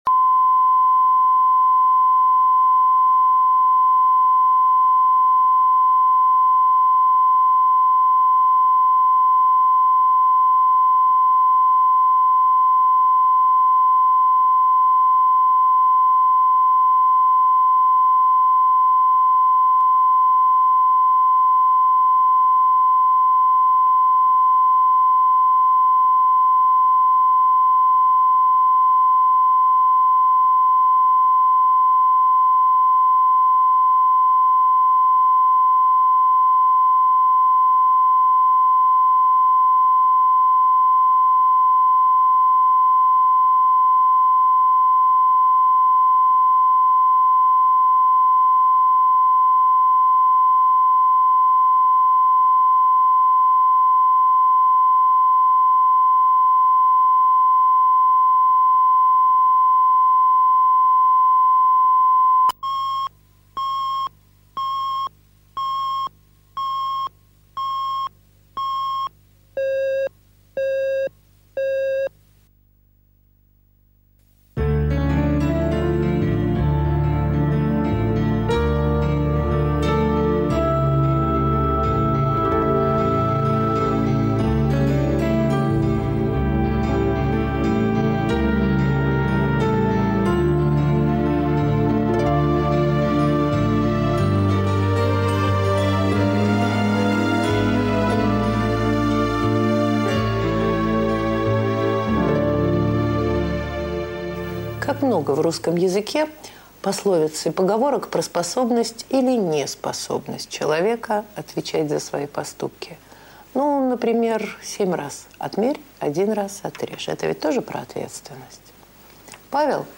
Аудиокнига Женские штучки | Библиотека аудиокниг
Прослушать и бесплатно скачать фрагмент аудиокниги